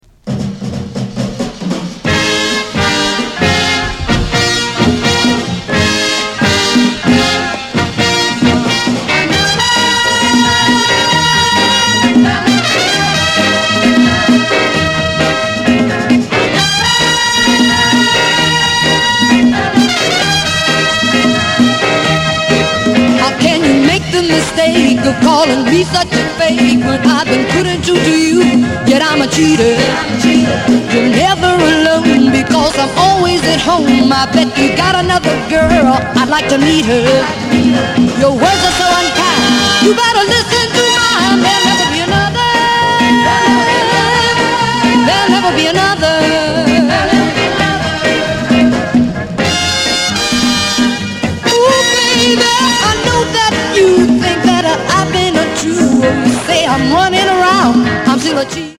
Classic album from the soul singer.